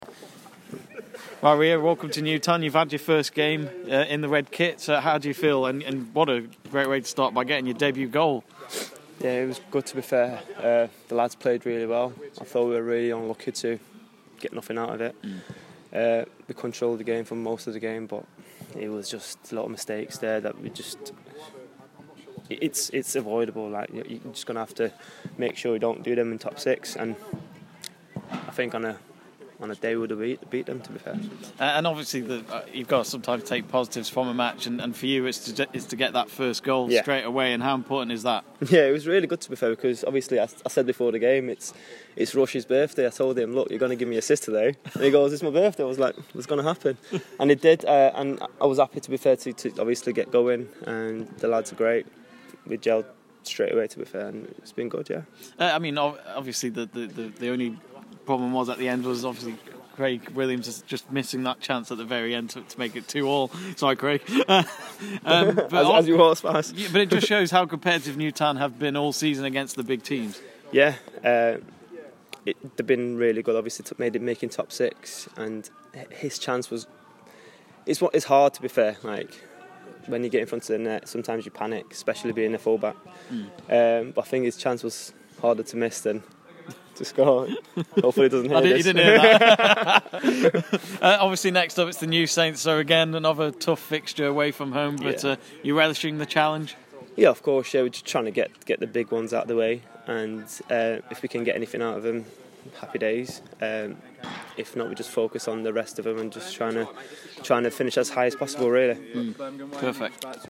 interview at Newtown - Robins 1-2 Nomads